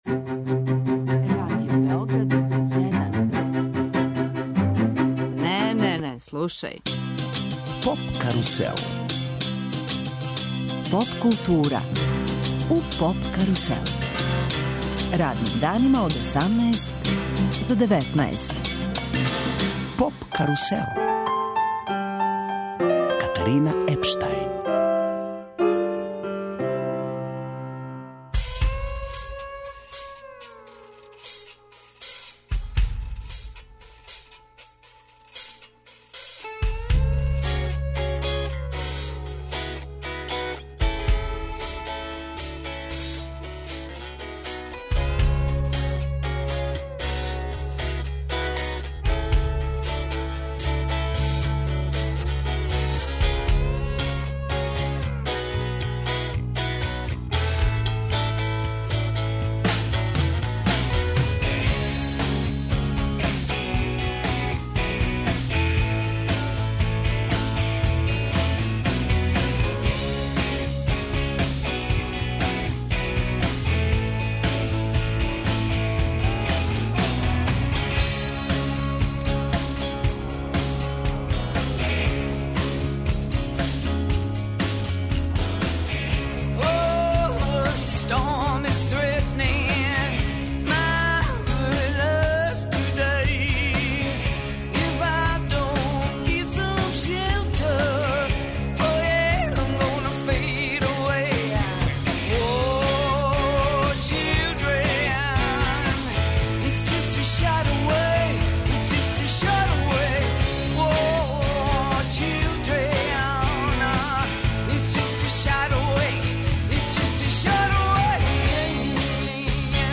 Гост данашње емисије је музичар Никола Чутурило - Чутура. Професионално се бави музиком од 1981. године, као члан група Силуете, Замба, Електрични оргазам и Рибља чорба.